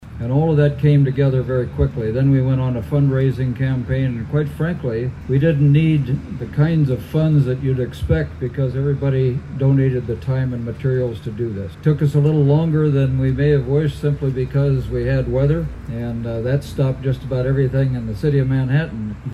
A speaker at the event was Manhattan Mayor Mike Dodson who said no tax dollars were used on the project.